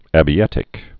(ăbē-ĕtĭk)